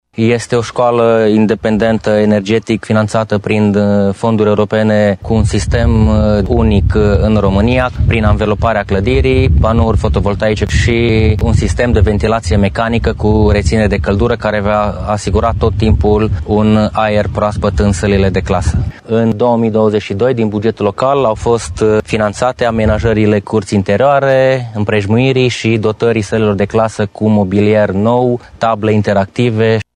Primarul din Sărmașu, Valer Botezan, crede că acum nu vor fi probleme cu plata facturilor: